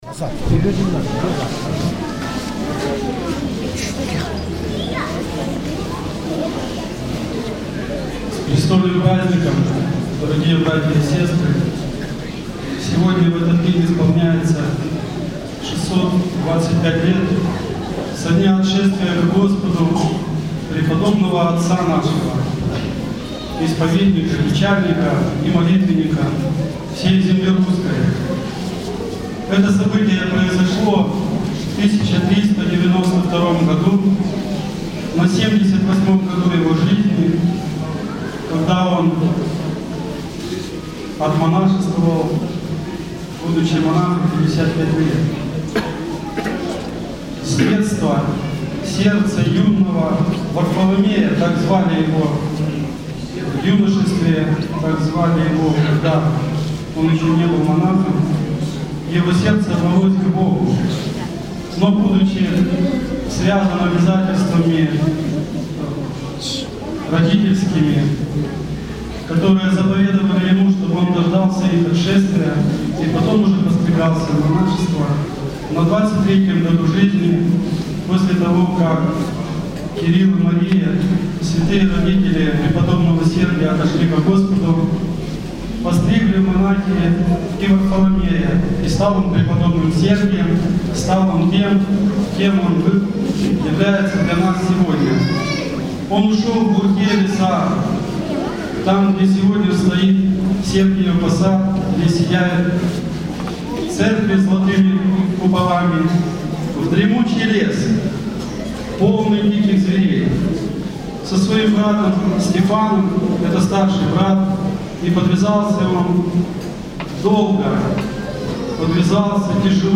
Проповедь в престольный праздник, осенний день памяти преподобного Сергия Радонежского